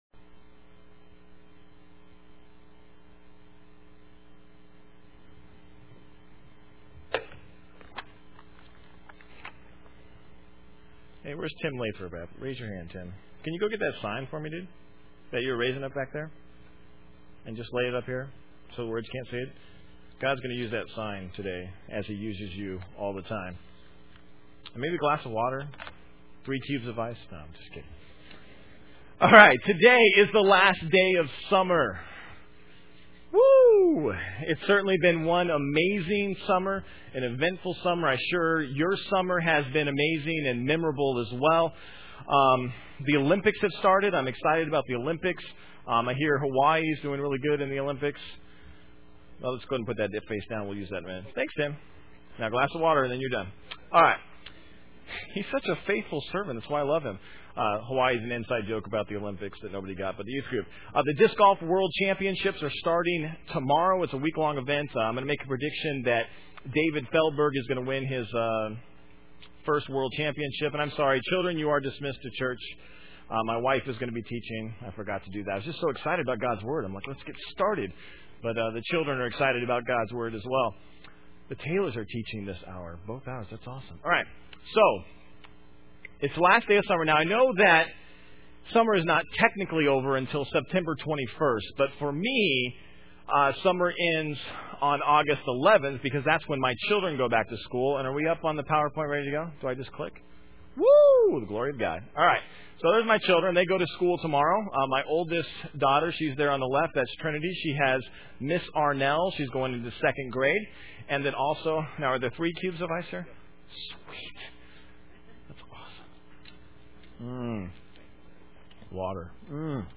"The Glory of God" - Main Service am